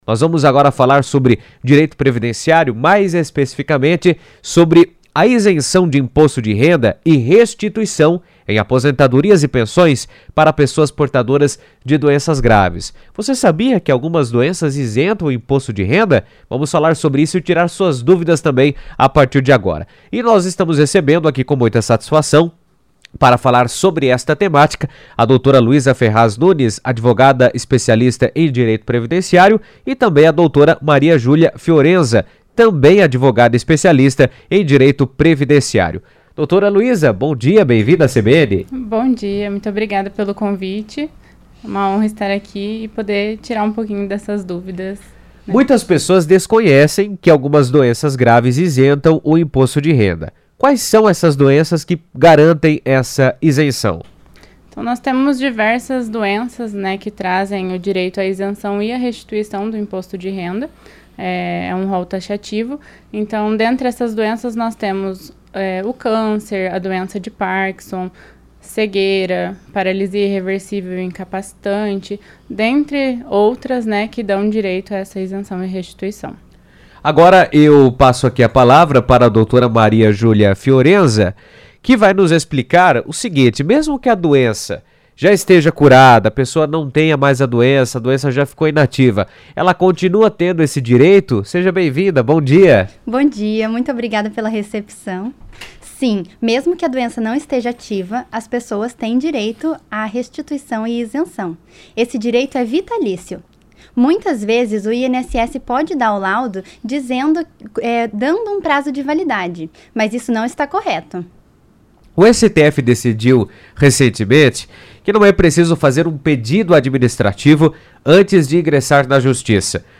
Editoriais